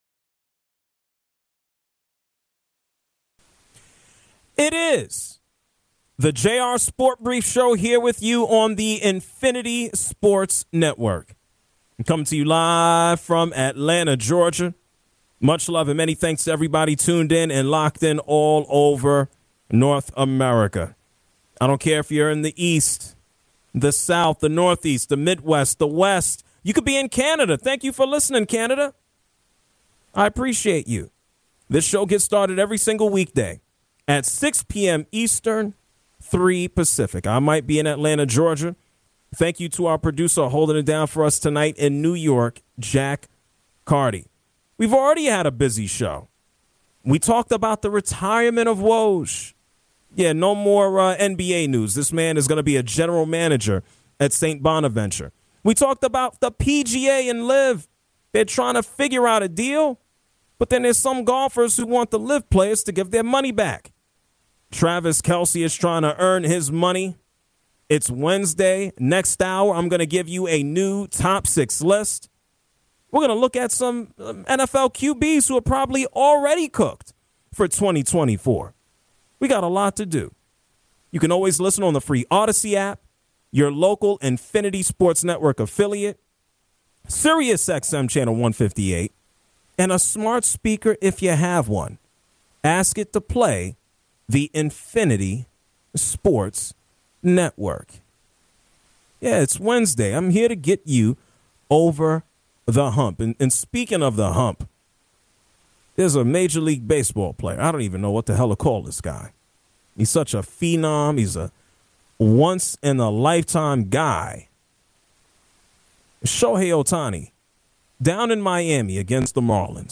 Eli Manning is a candidate for the hall of Fame is he deserving? interview